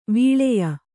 ♪ vīḷeya